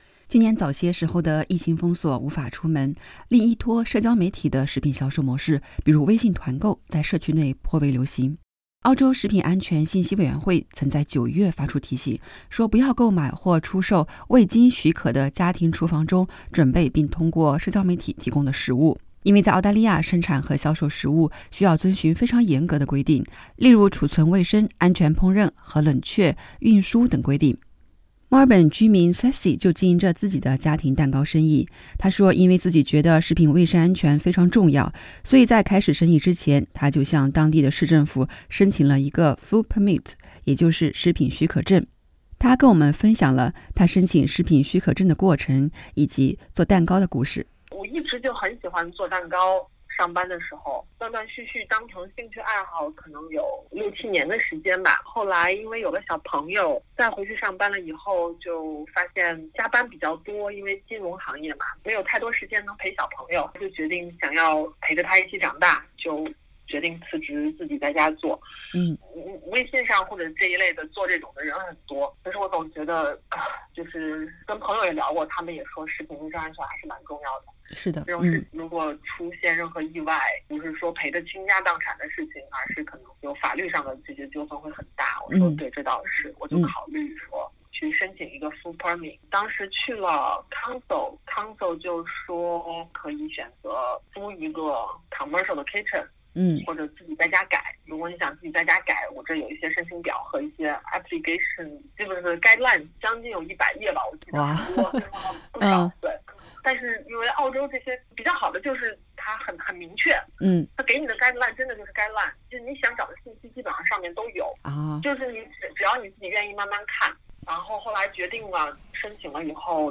点击文首图片收听完整采访。